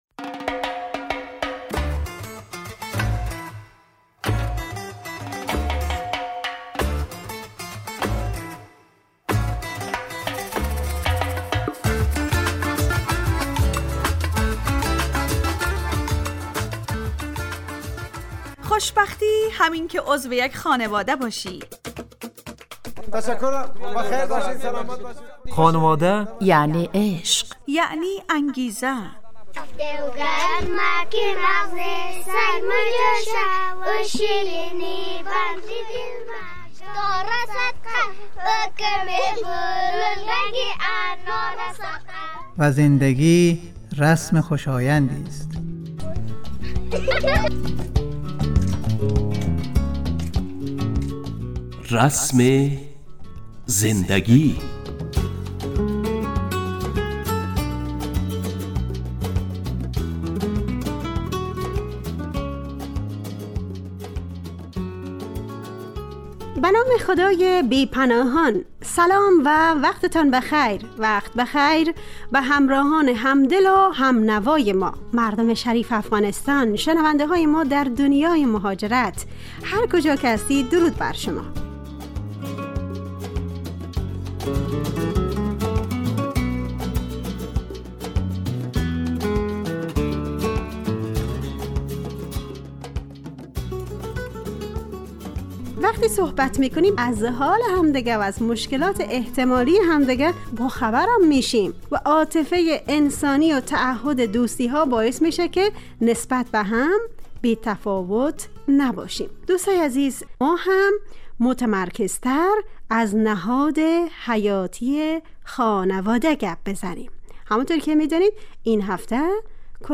رسم زندگی_ برنامه خانواده رادیو دری ___دوشنبه 2 تیر 404 ___موضوع_ خانه و خانواده _ گوینده و تهیه کننده